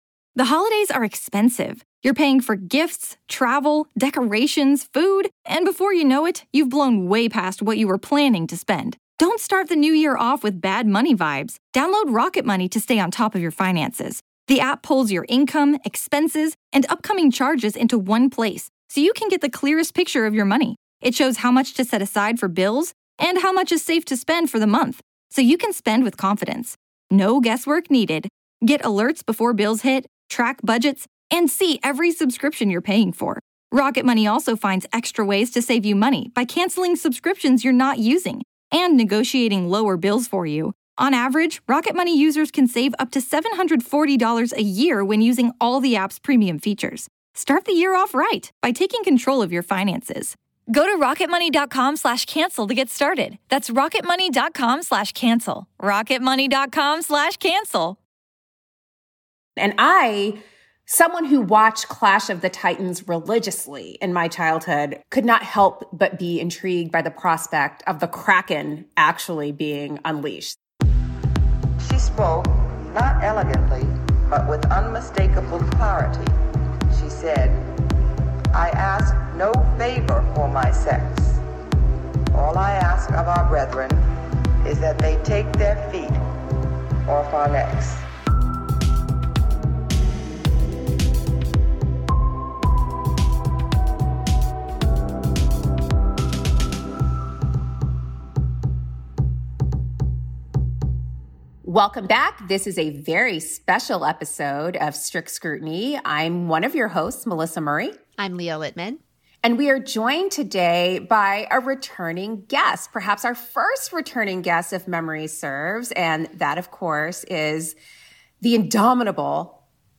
They also provide some dramatic readings and reenactments of some of the hearings and filings from the (poorly run) coup attempt.